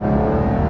CosmicRageSounds / ogg / general / highway / oldcar / ac3.ogg